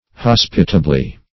Hospitably \Hos"pi*ta*bly\, adv. In a hospitable manner.